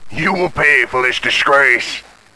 Human Male, Age 28